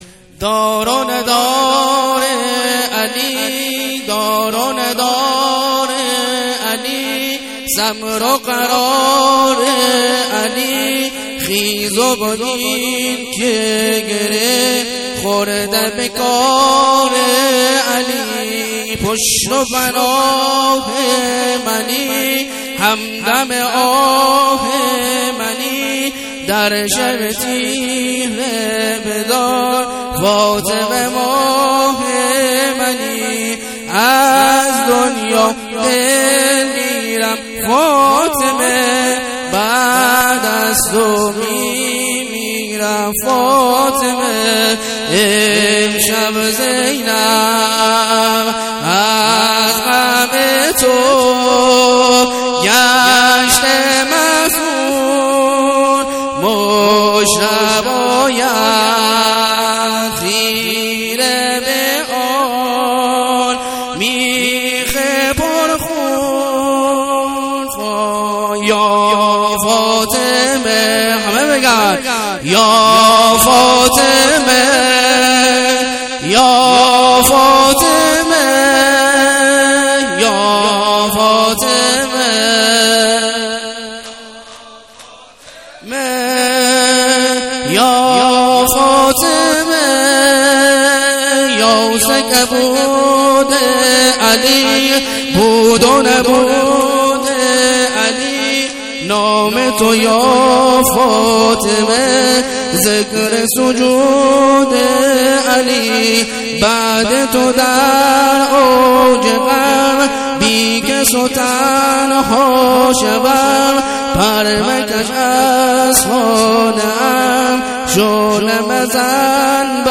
مداحی
مراسمات هیئت